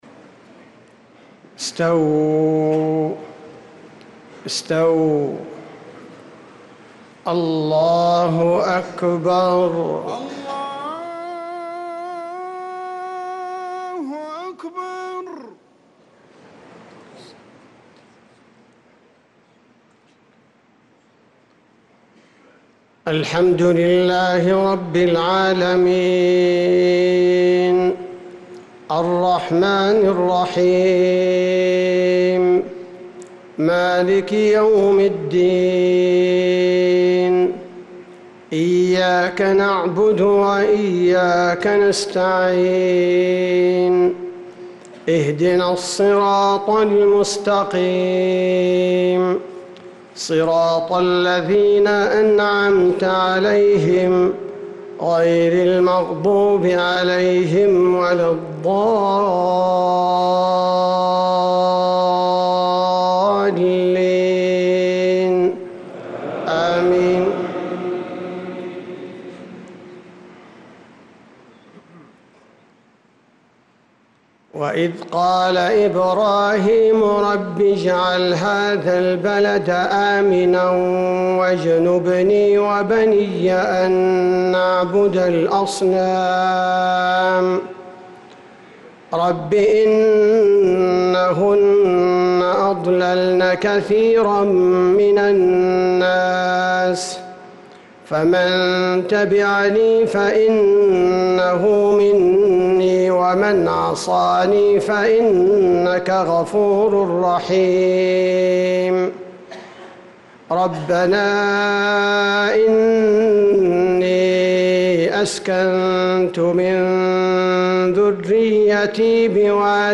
صلاة المغرب للقارئ عبدالباري الثبيتي 24 ربيع الأول 1446 هـ
تِلَاوَات الْحَرَمَيْن .